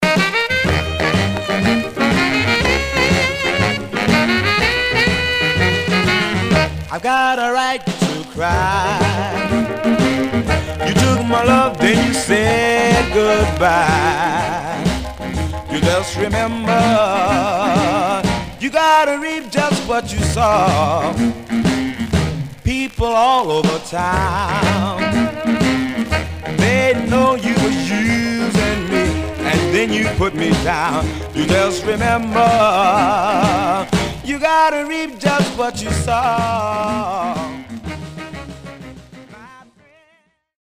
Stereo/mono Mono
Rythm and Blues